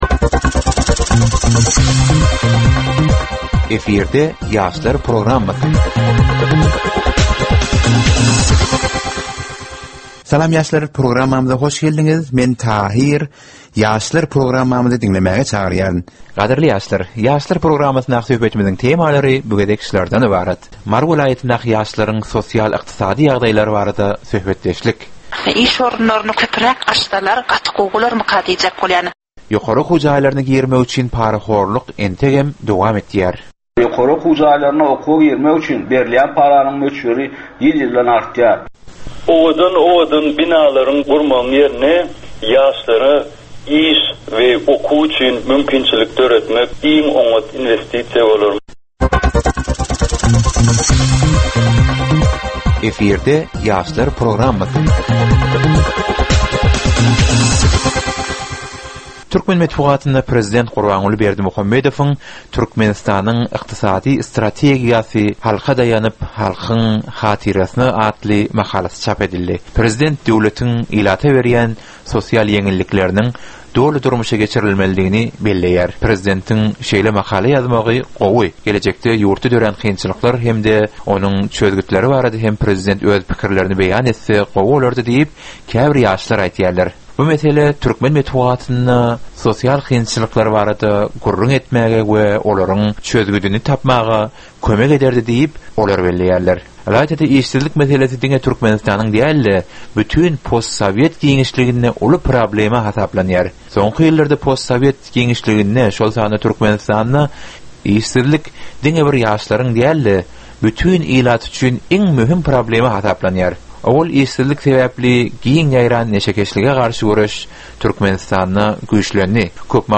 Gepleşigiň dowamynda aýdym-sazlar hem eşitdirilýär.